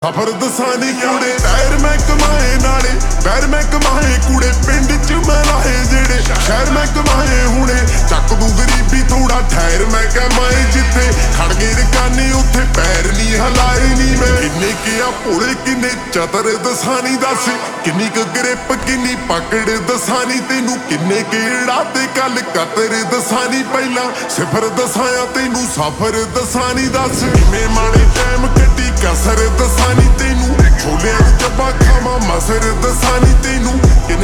Punjabi Songs
( Slowed + Reverb)